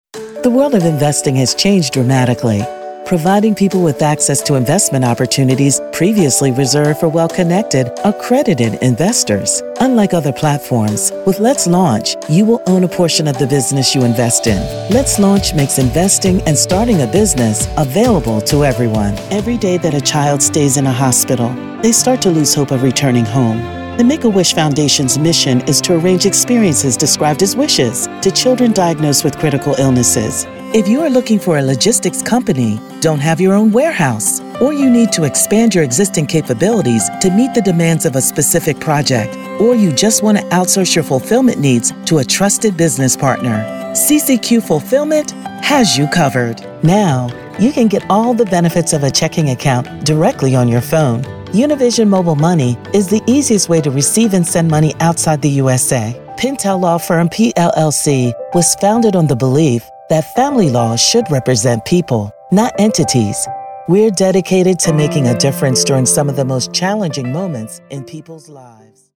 Commercial Demo
Mid-Atlantic region
Middle Aged